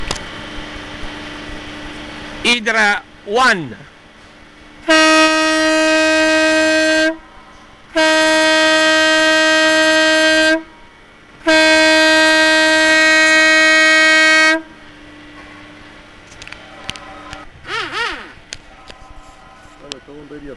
Fisa avertisseur Hydra 1 12V | 146207